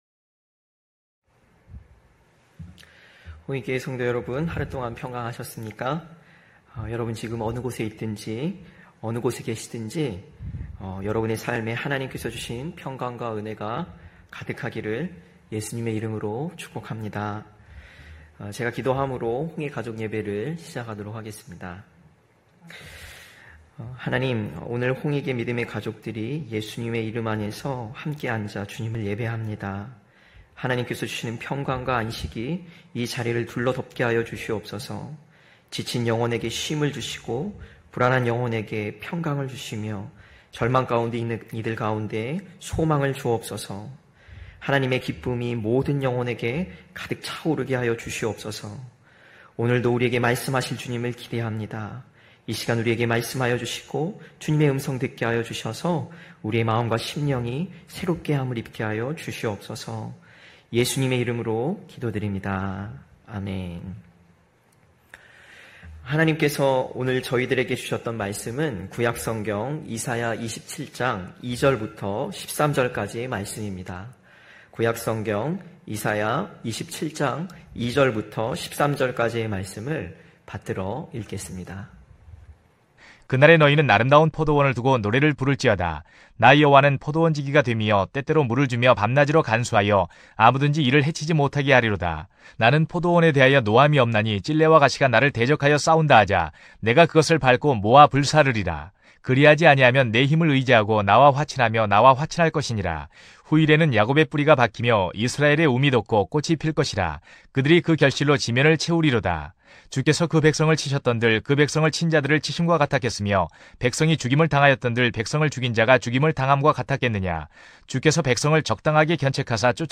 9시홍익가족예배(8월15일).mp3